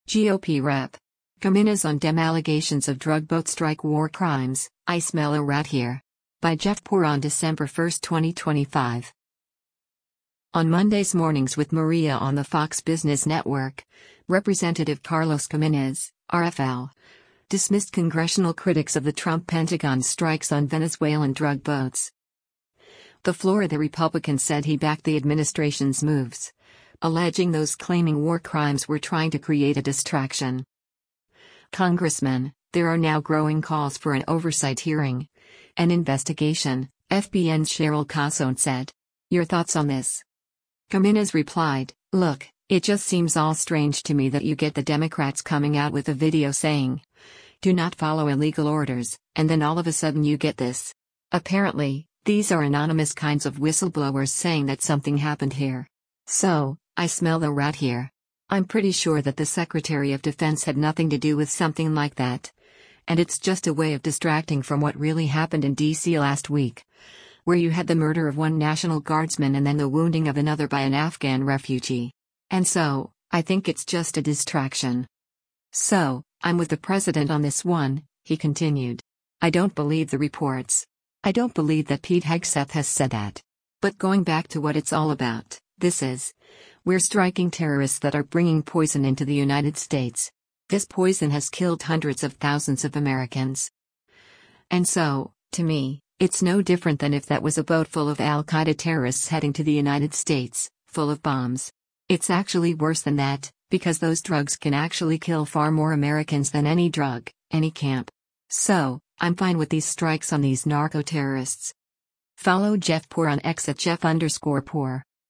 On Monday’s “Mornings with Maria” on the Fox Business Network, Rep. Carlos Gimenez (R-FL) dismissed congressional critics of the Trump Pentagon’s strikes on Venezuelan drug boats.